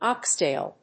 音節óx・tàil
アクセント・音節óx・tàil